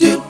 Hip Vcl Kord-C#.wav